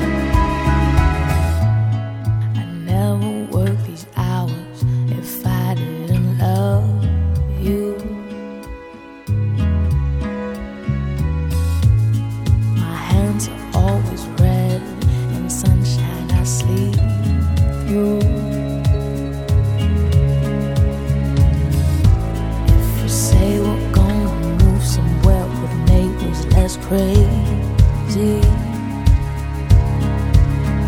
Rock et variétés internationales